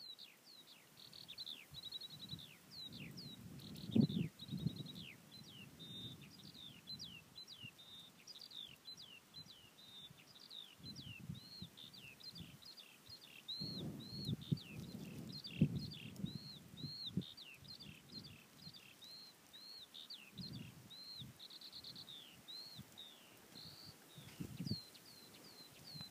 Everywhere I walked they seemed to be above me. I whipped out my iPhone on one walk and wondered if its microphone was remotely good enough to record the sound.
And only manage 20 clear seconds with no other extraneous noise.
Note for the programme: Skylarks recorded in North Norfolk on a magical June day.
skylarks-lively.m4a